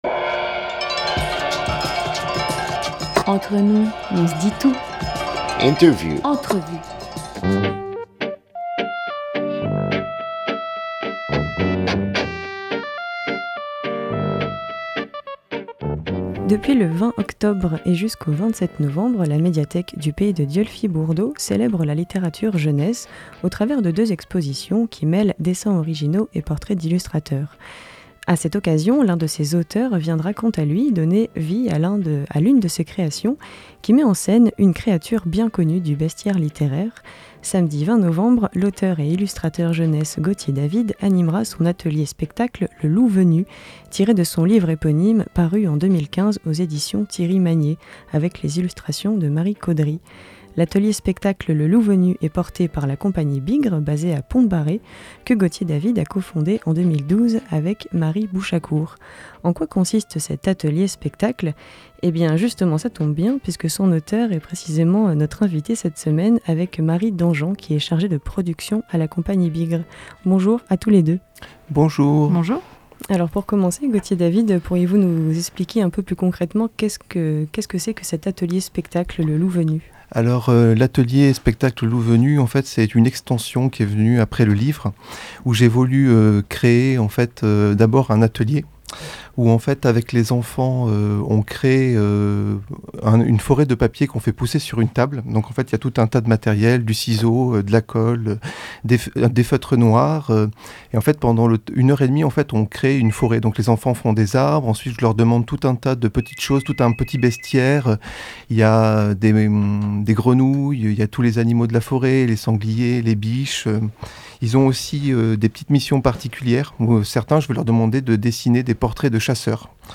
2 novembre 2021 12:27 | Interview